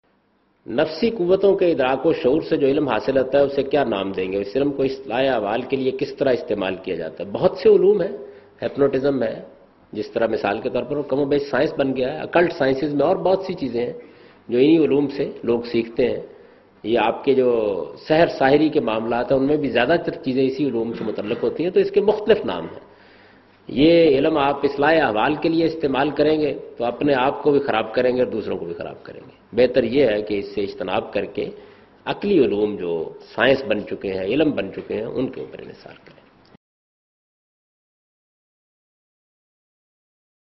Category: Reflections / Questions_Answers /
Javed Ahmad Ghamidi answers a question regarding "Learning Occult Disciplines for Corrective Measures"